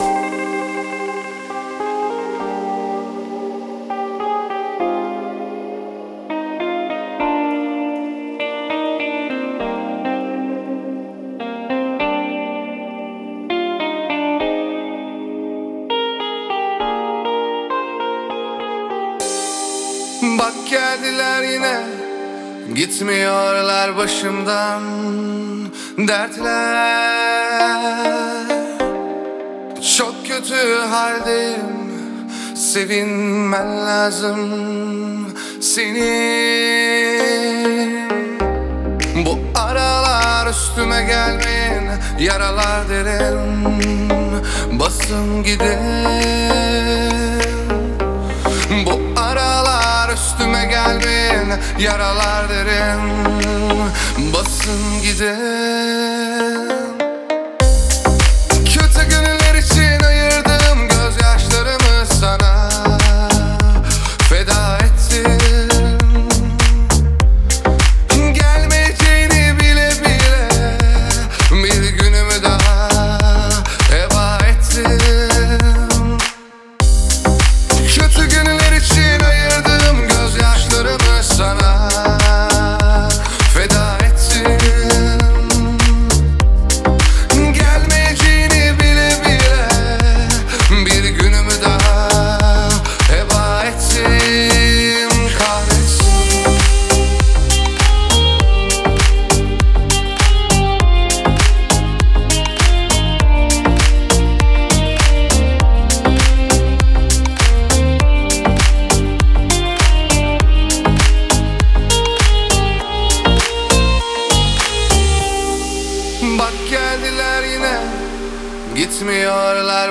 созданная в жанре турецкой народной музыки